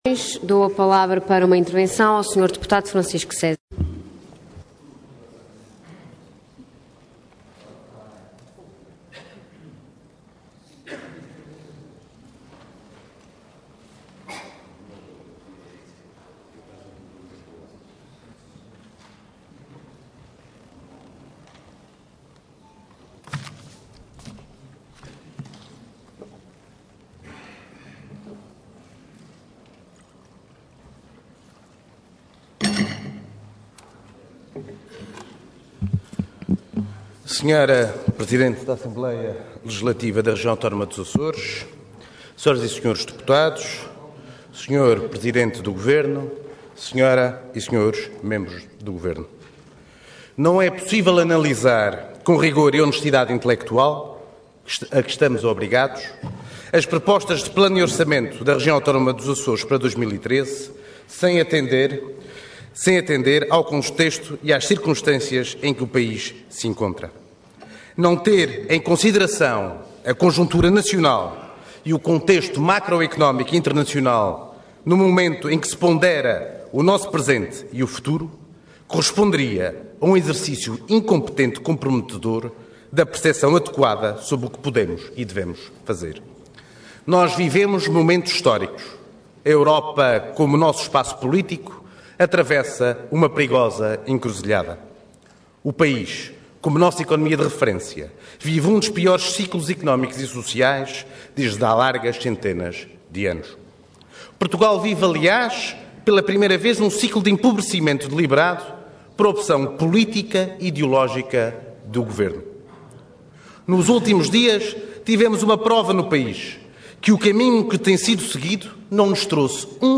Intervenção Intervenção de Tribuna Orador Francisco César Cargo Deputado Entidade PS